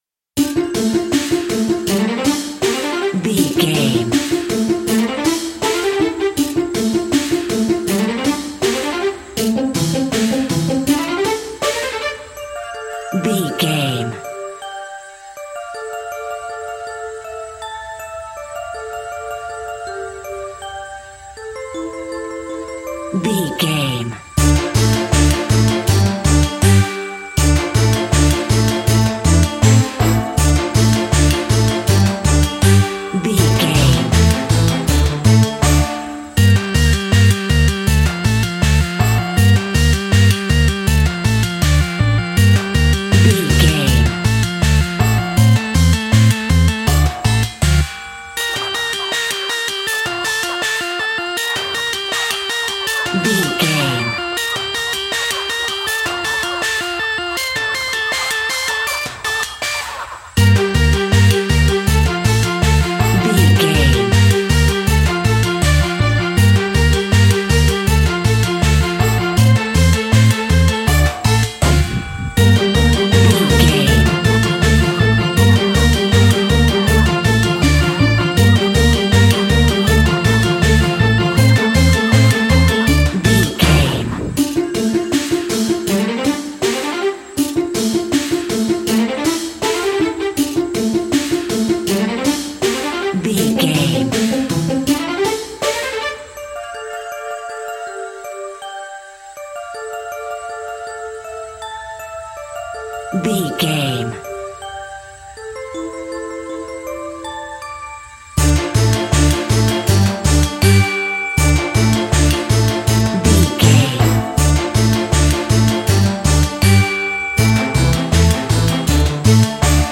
Aeolian/Minor
D
tension
ominous
eerie
synthesiser
drum machine
spooky
instrumentals